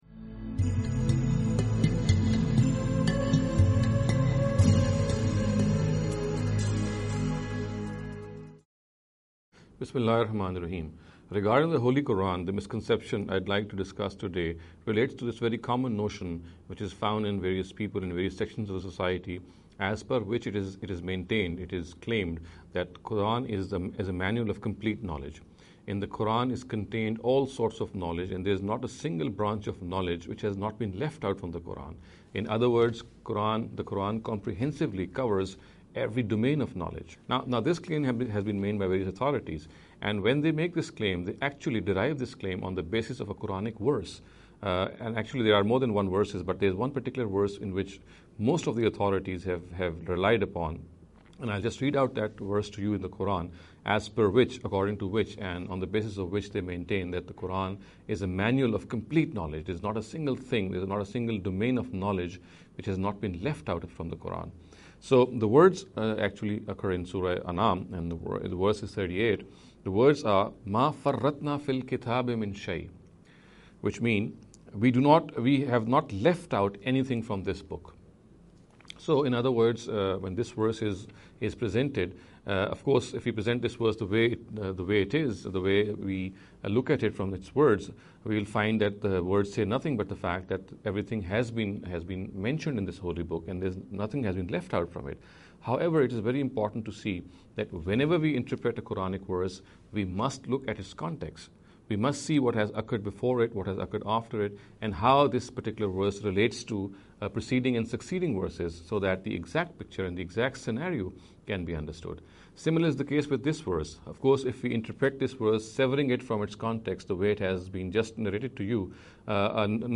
This lecture series will deal with some misconception regarding the Holy Qur’an. In every lecture he will be dealing with a question in a short and very concise manner. This sitting is an attempt to deal with the question 'Is the Qur’an a Manual of Complete Knowledge?’.